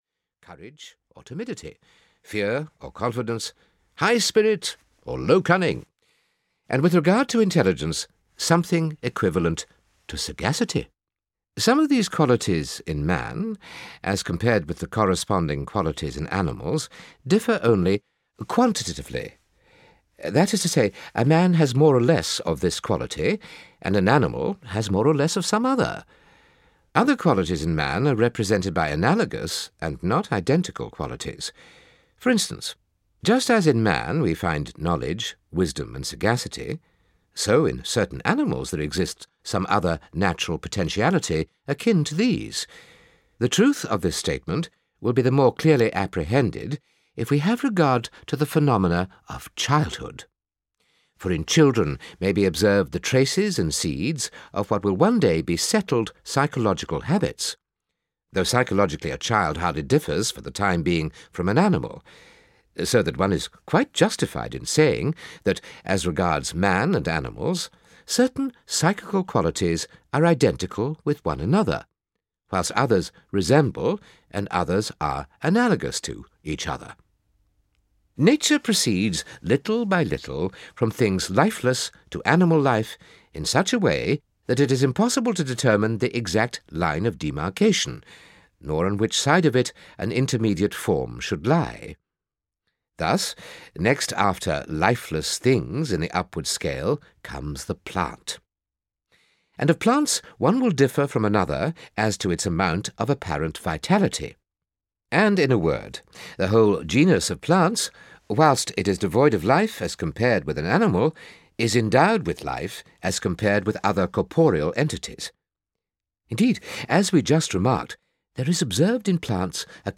Aristotle – An Introduction (EN) audiokniha
Ukázka z knihy